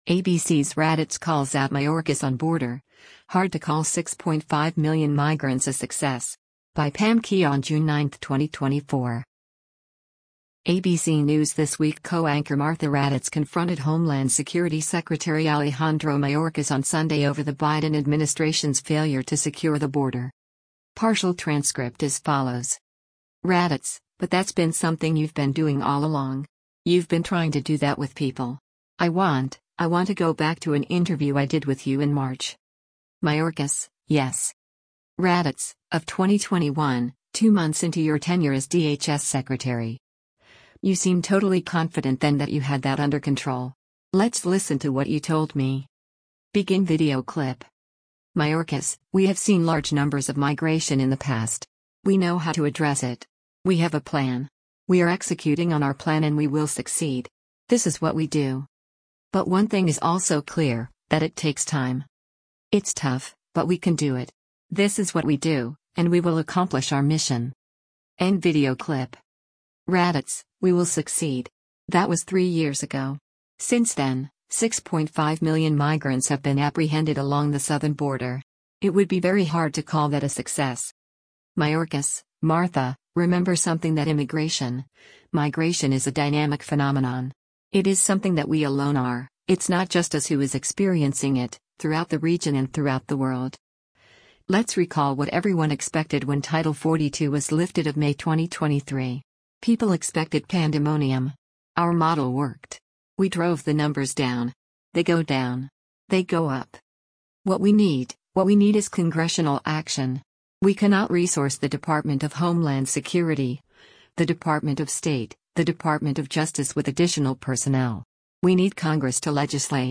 ABC News’ “This Week” co-anchor Martha Raddatz confronted Homeland Security Secretary Alejandro Mayorkas on Sunday over the Biden administration’s failure to secure the border.